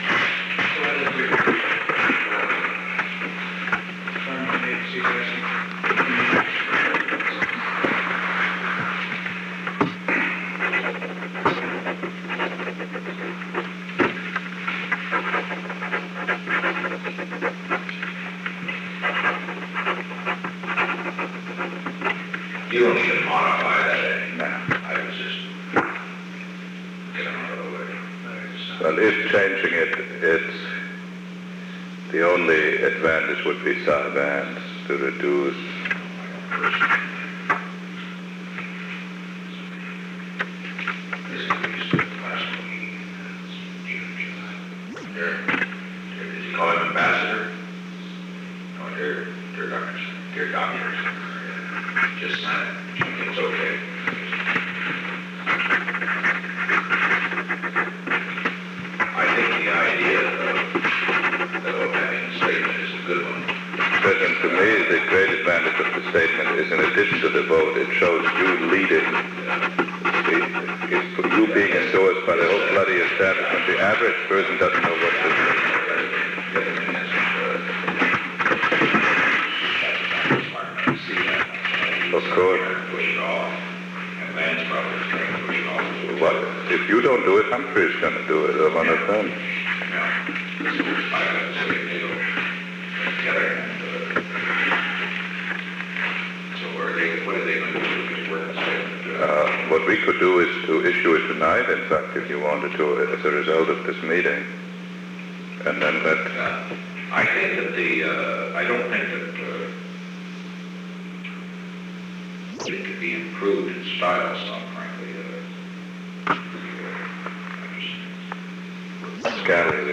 On May 13, 1971, President Richard M. Nixon, Alexander P. Butterfield, and Henry A. Kissinger met in the Oval Office of the White House at an unknown time between 6:03 pm and 6:28 pm. The Oval Office taping system captured this recording, which is known as Conversation 498-018 of the White House Tapes.
Recording Device: Oval Office